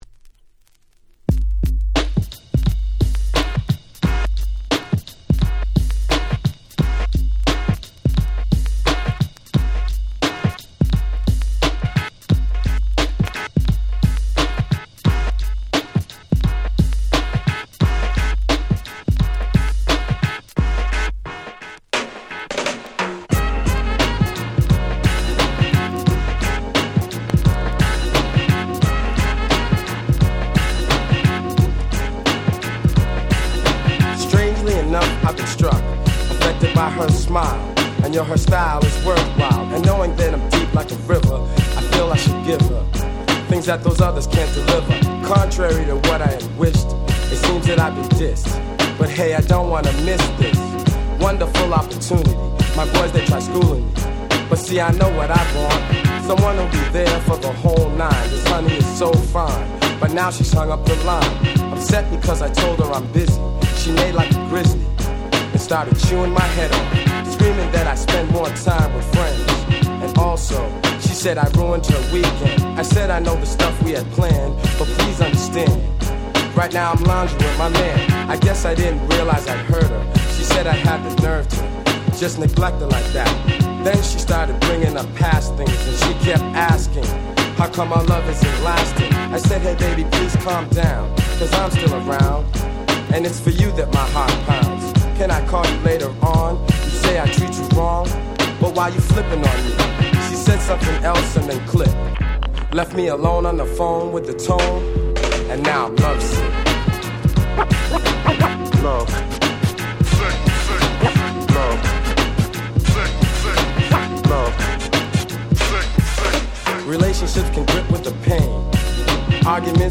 91' Hip Hop Super Classics !!
90's Boom Bap ブーンバップ